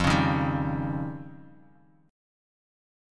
F7sus2#5 chord